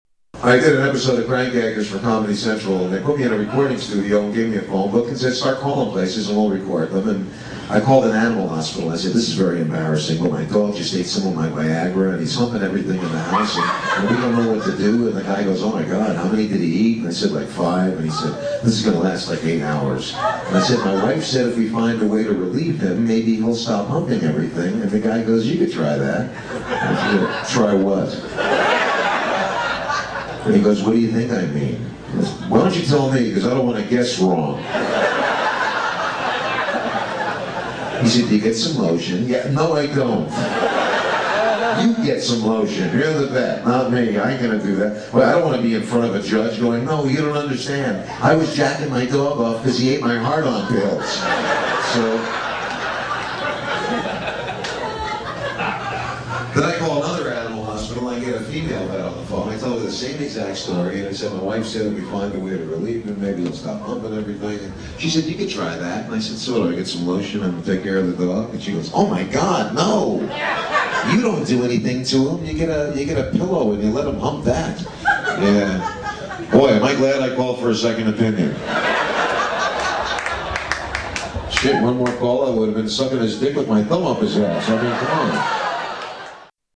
Category: Comedians   Right: Personal
Tags: Comedian Robert Schimmel clips Robert Schimmel audio Stand-up comedian Robert Schimmel